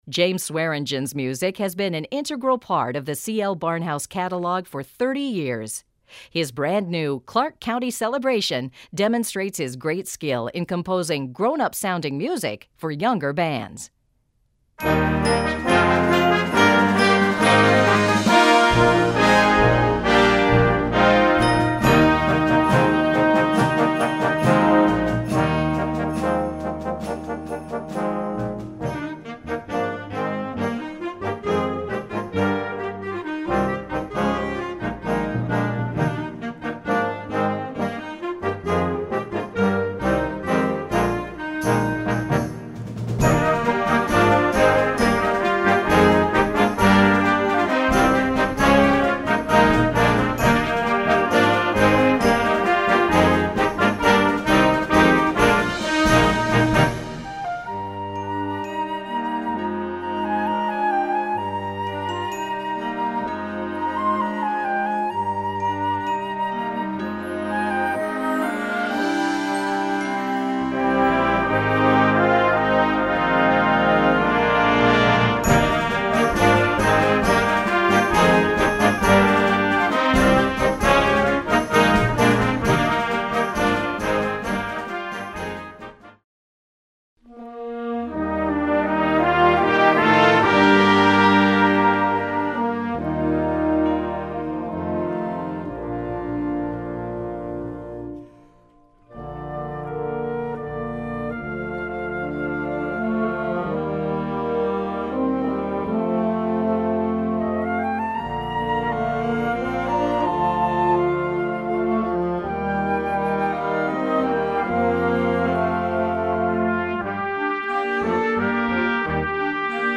Categorie Harmonie/Fanfare/Brass-orkest
Subcategorie Concertmuziek
Bezetting Ha (harmonieorkest); YB (jeugdorkest)